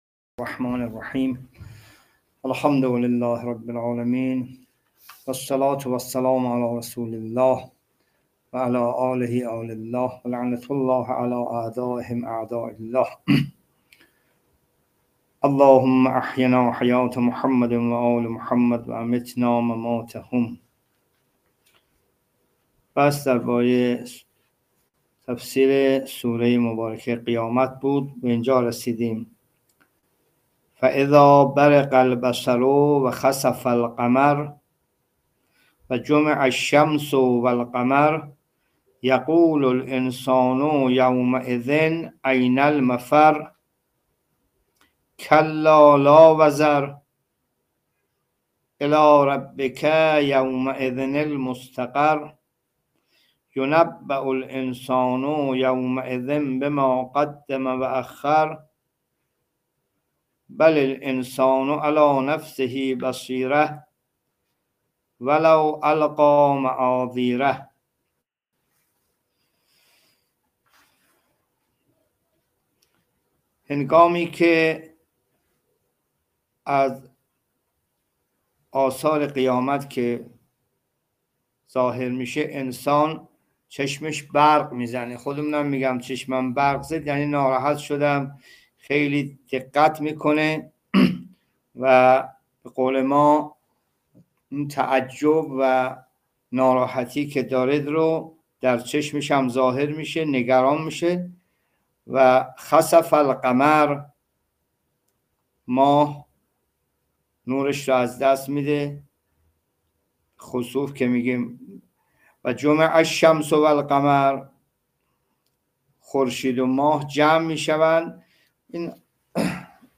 جلسه تفسیر قرآن(3) سوره قیامت